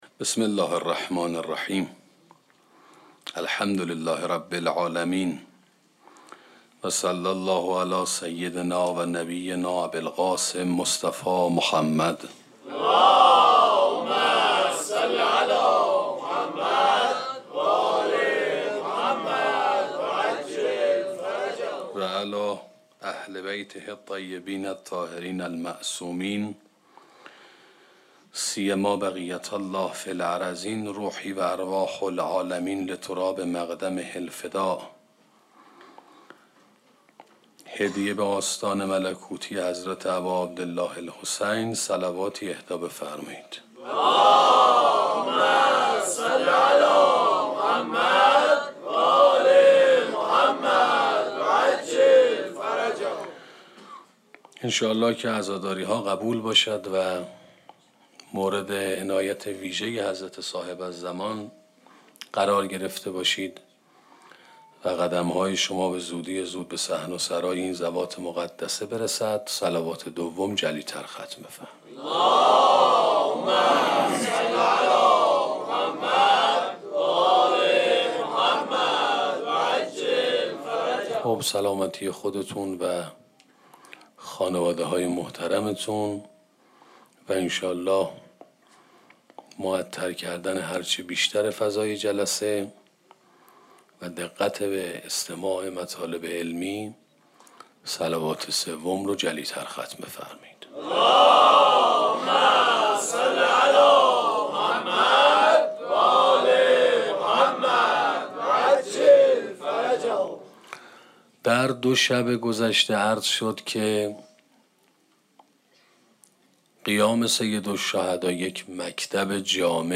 سخنرانی امر به معروف و نهی از منکر